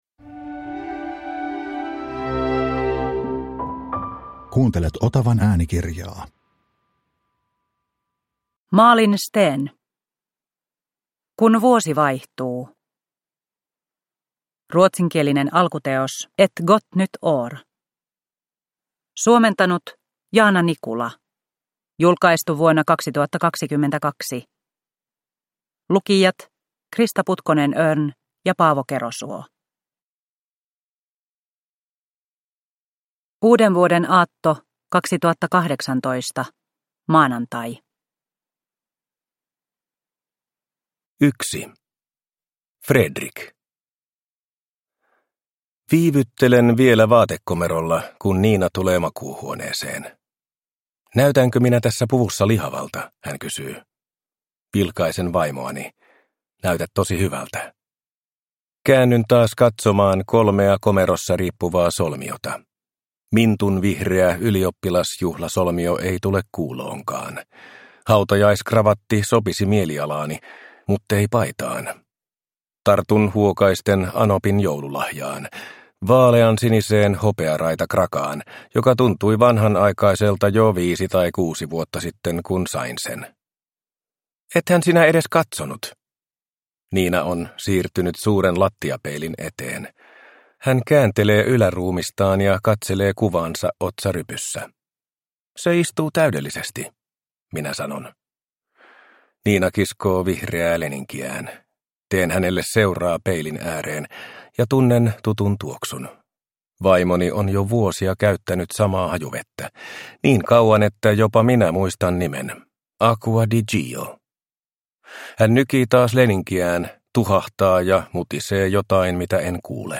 Kun vuosi vaihtuu – Ljudbok – Laddas ner